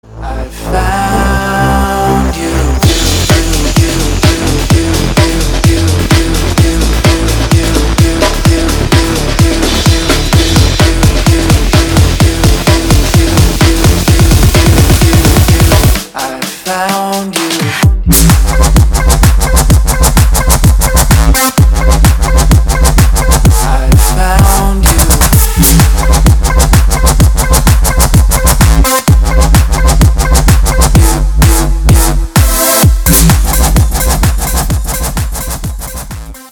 Android, Elektronisk musik